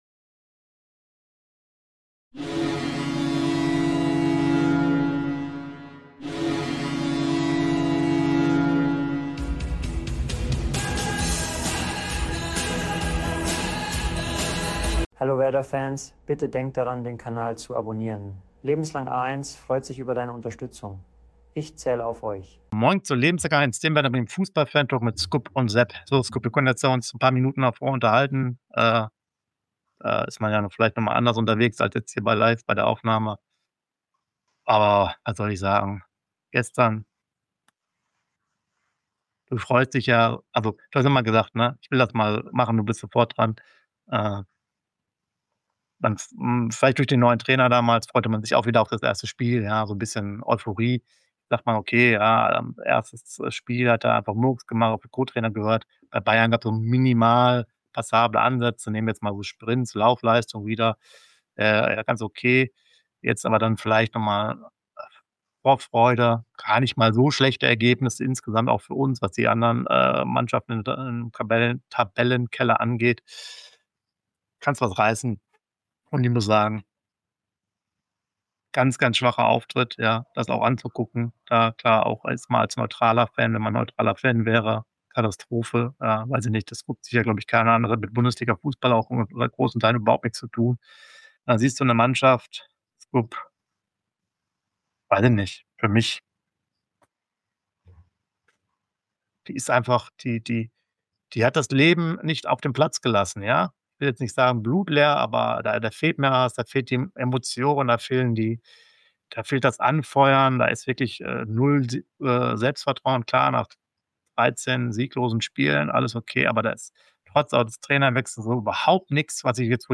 Werder Bremen - Fußball Fantalk Lebenslang-A1